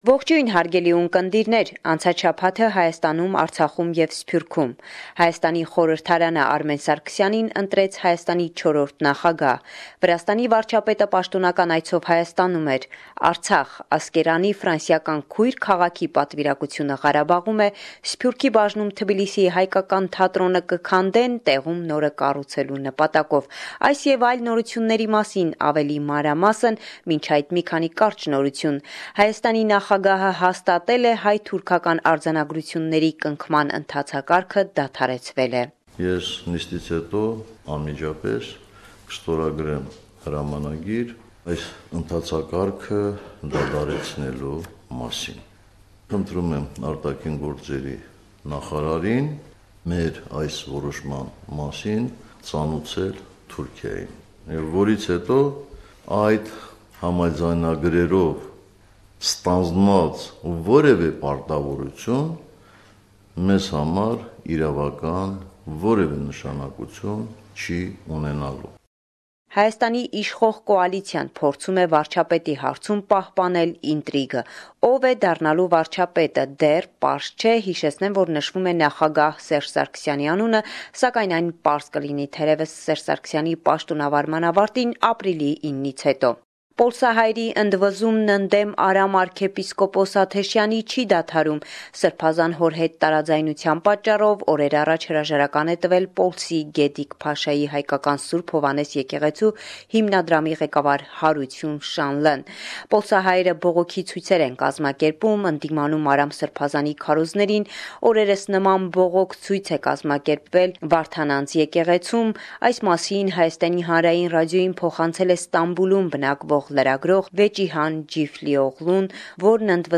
Վերջին Լուրերը – 6 Մարտ 2018